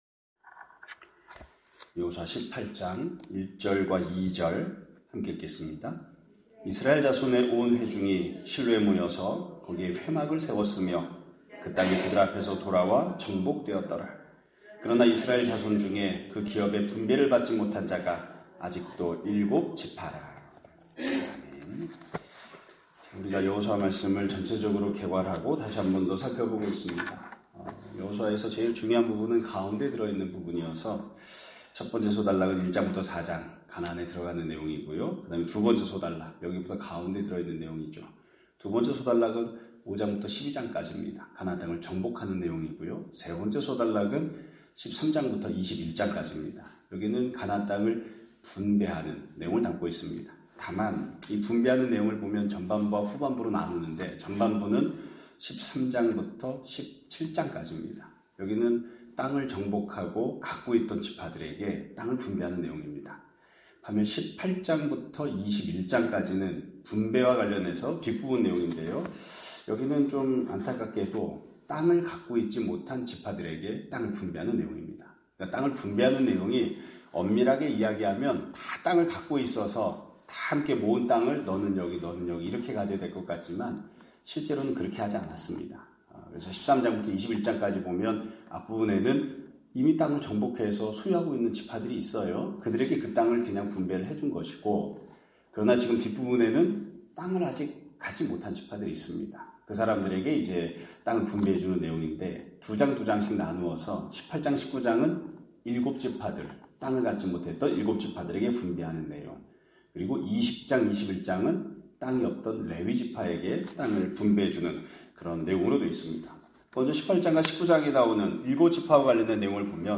2025년 3월 14일(금요일) <아침예배> 설교입니다.